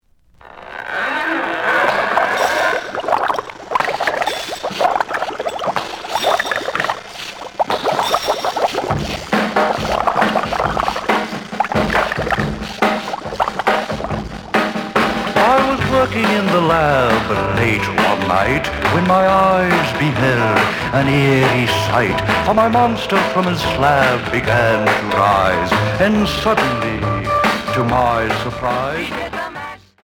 試聴は実際のレコードから録音しています。
●Genre: Rock / Pop
●Record Grading: VG~VG+ (両面のラベルに若干のダメージ。盤に歪み。プレイOK。)